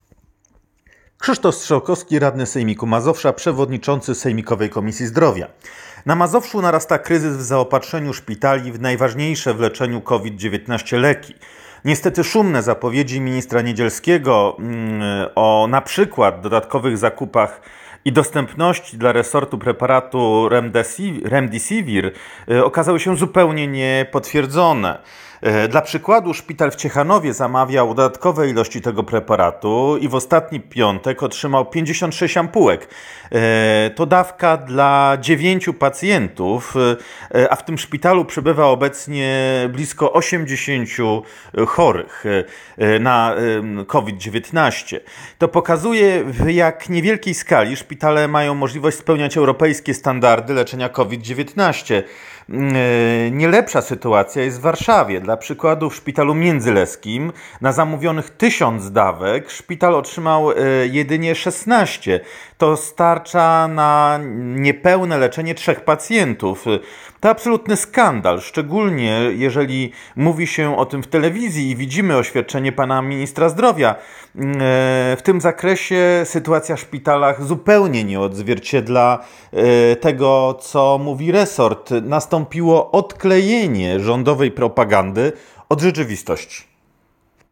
Wypowiedź.